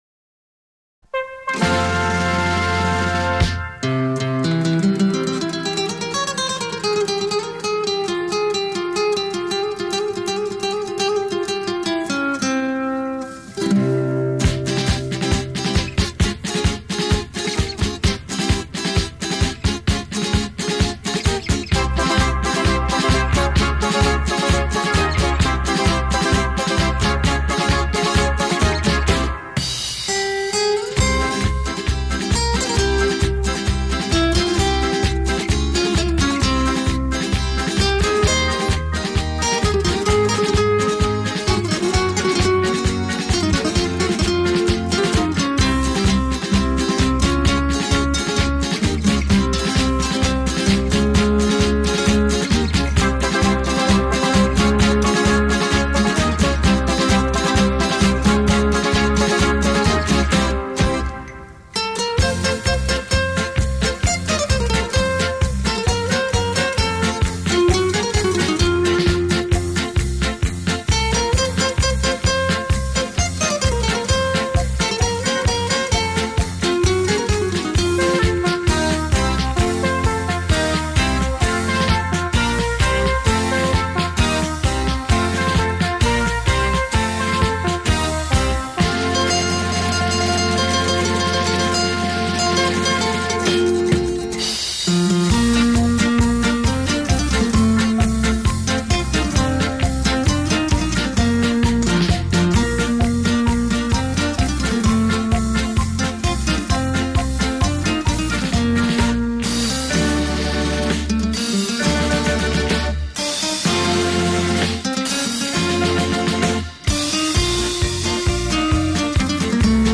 新颖的镭射试音碟靓音出击，时尚曲目激情电子琴的演绎；